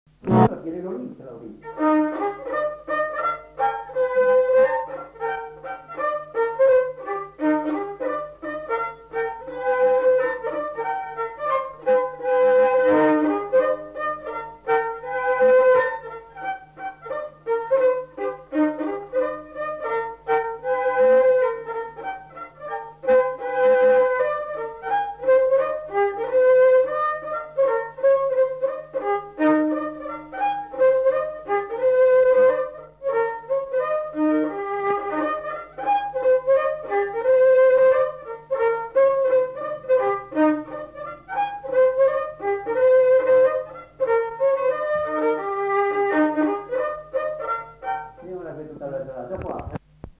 Lieu : Casteljaloux
Genre : morceau instrumental
Instrument de musique : violon
Danse : scottish